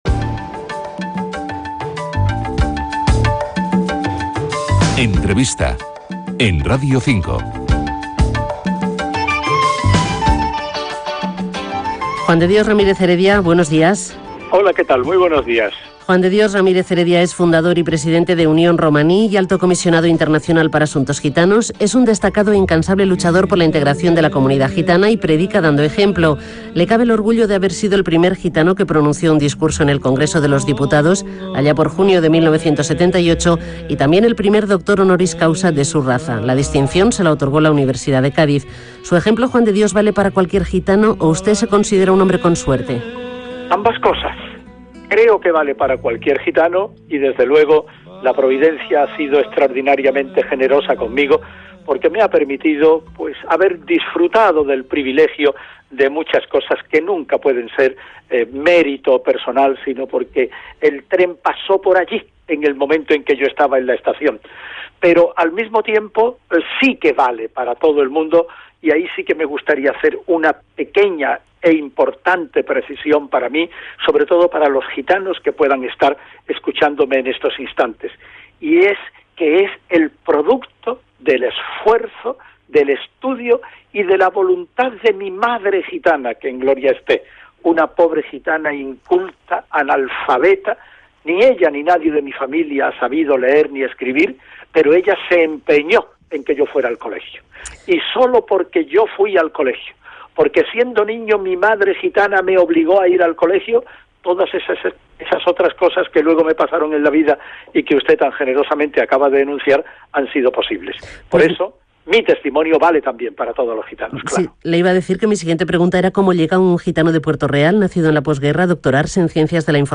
Entrevista (Radio 5)